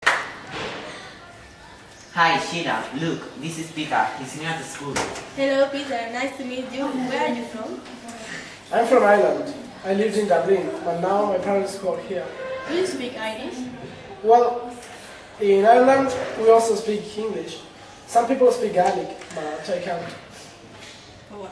Everyday conversations
Dos chicos de pié en los pasillos de un instituto, uno frente a otro, mantienen una conversación.